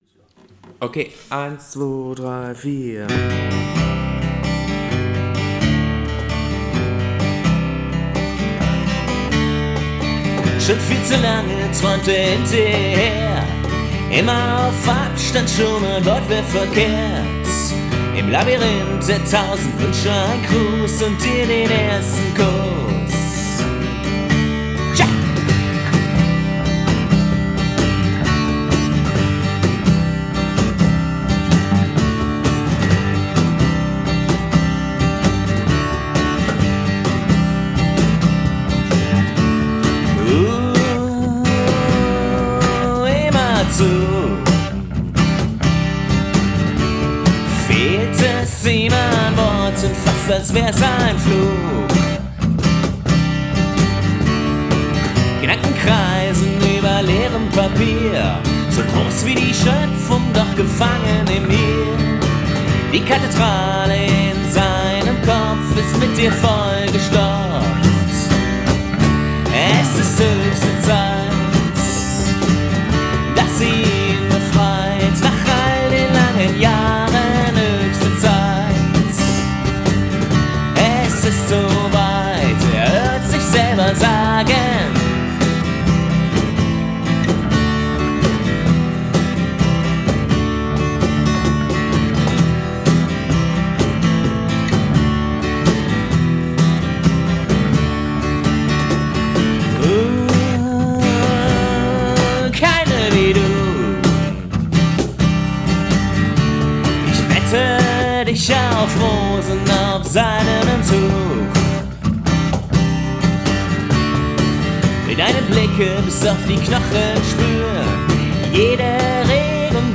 (Akustik)